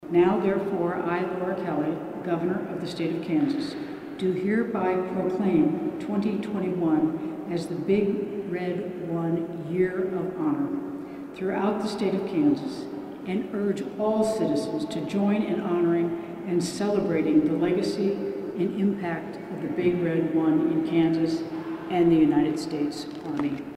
Kansas Gov. Laura Kelly honored Fort Riley’s 1st Infantry Division Tuesday, signing a proclamation at the Statehouse.